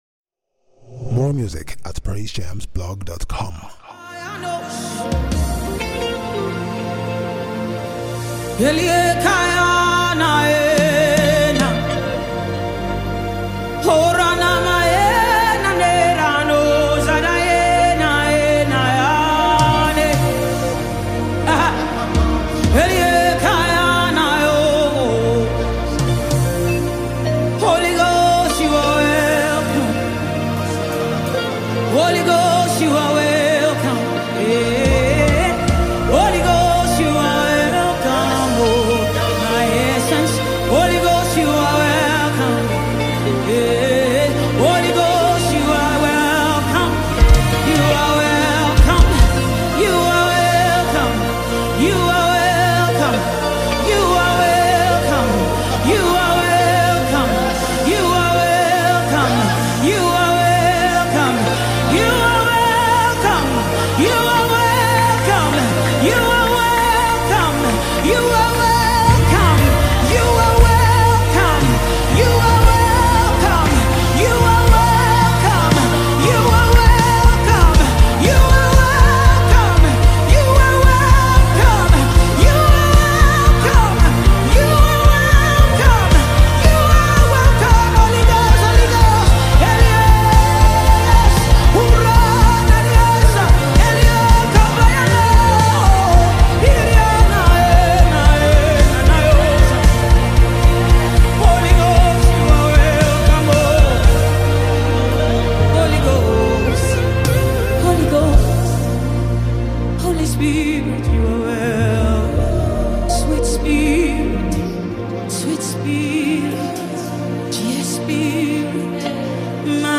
worship anthem